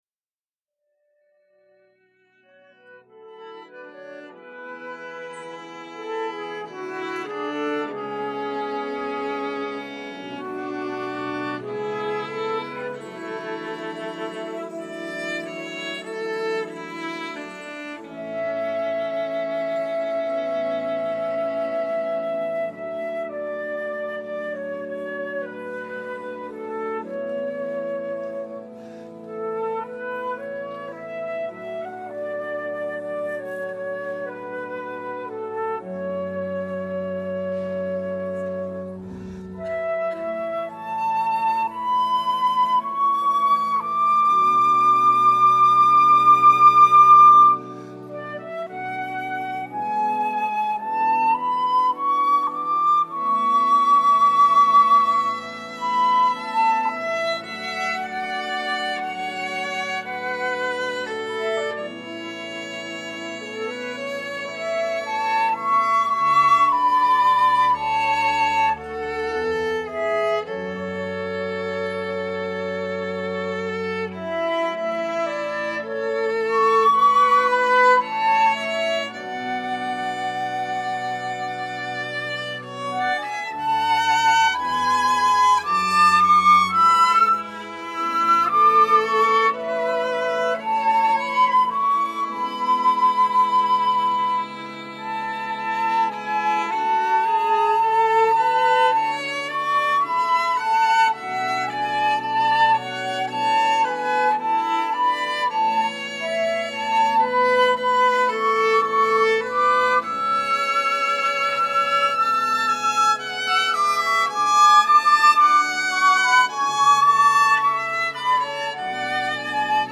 Organo
Violino
Flauto traverso
Flauto dolce
Chitarra
Percussioni
Voci Soliste
Soprani
Contralti
Tenori
Bassi